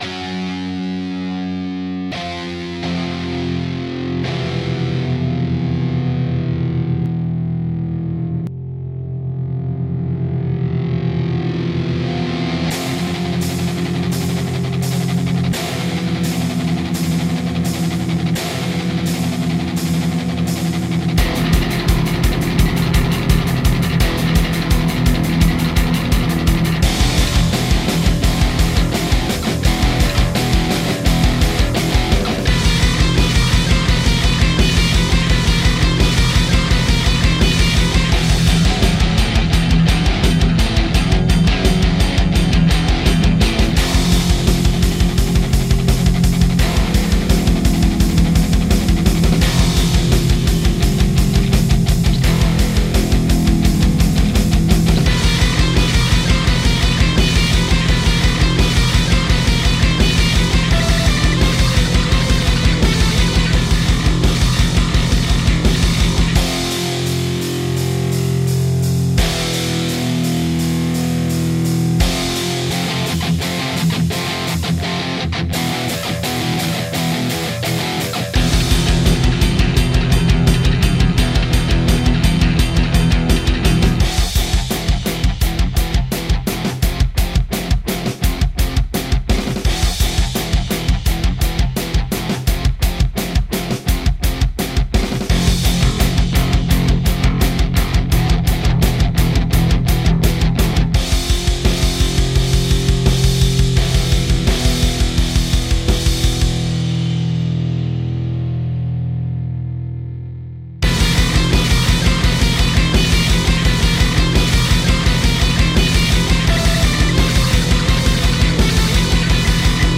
I wanted to create a creepy, oceanic vibe, something that plays in turbulant waters..
Music / Rock
It's got a killer sound.